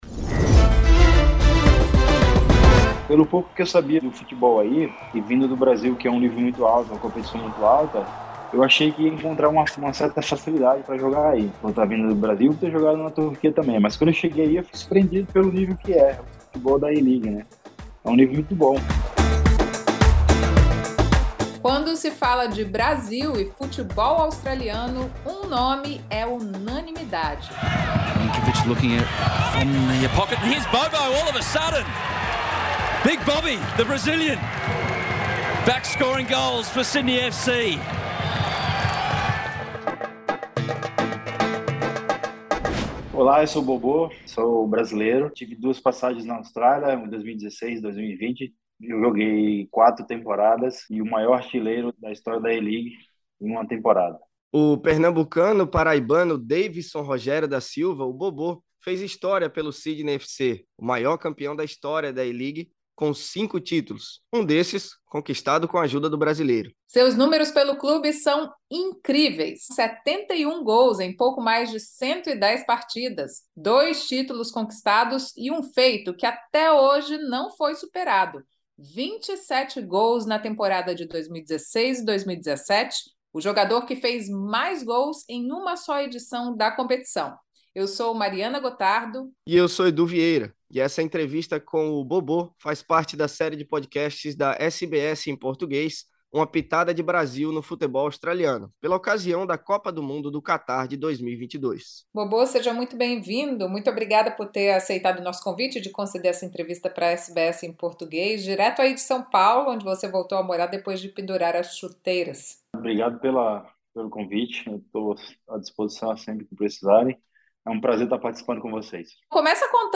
Bobô concedeu essa entrevista de São Paulo, onde voltou a morar depois de pendurar as chuteiras este ano depois da sua segunda passagem pelo Sydney FC.